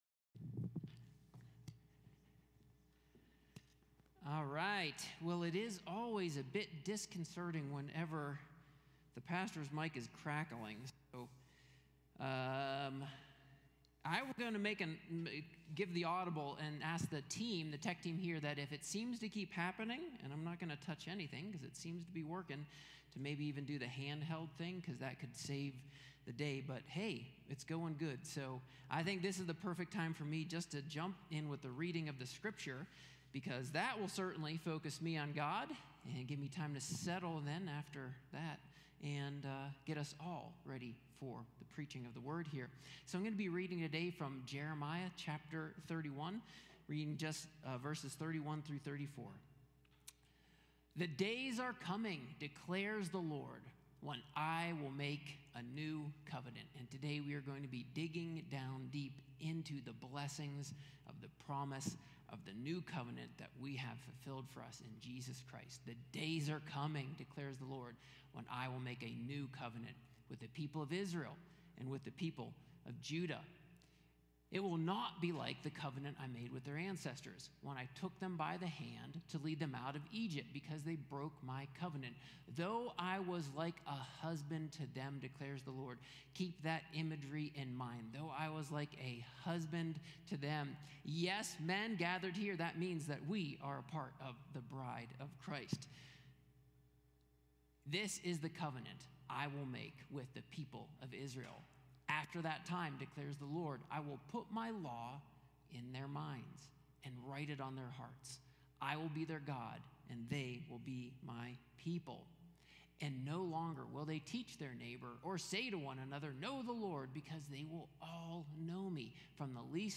A sermon from the series "The King's Gambit."